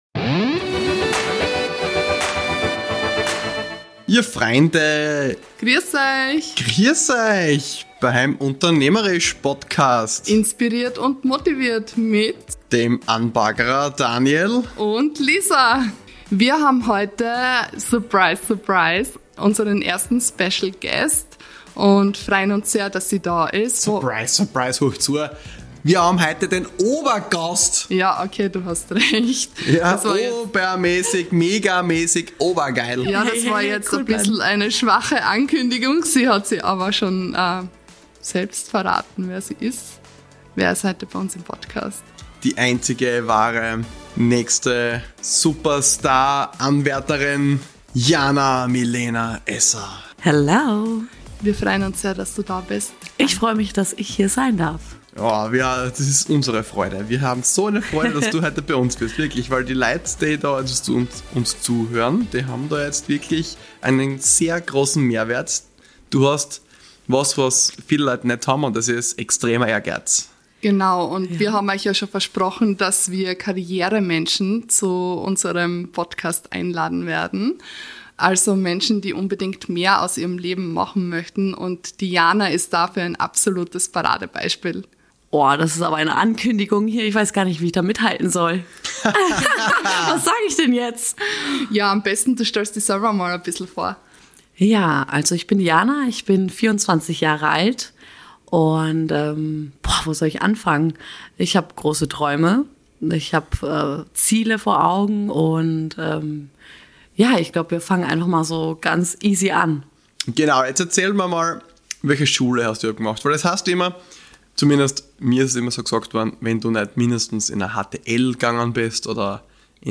Episode 3 - There's No Business Like Showbusiness (Interview